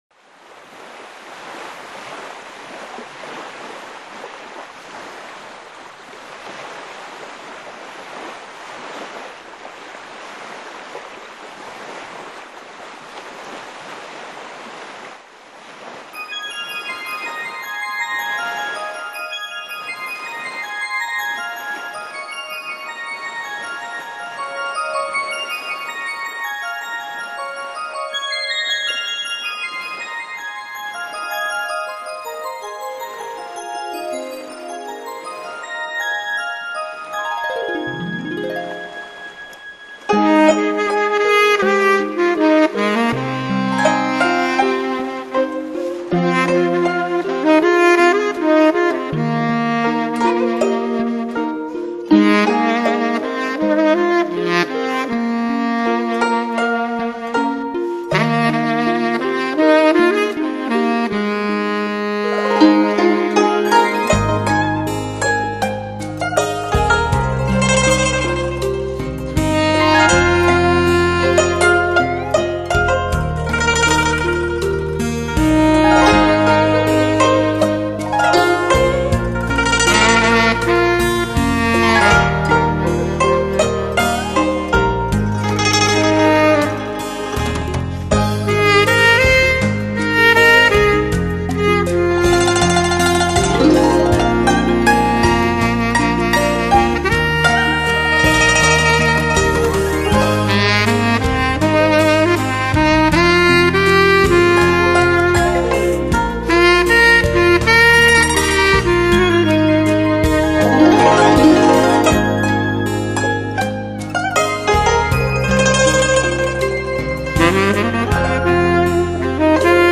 采用世界最新的美国DTS-ES顶级编码器，带来超乎想像震撼性的6.1环绕新体验。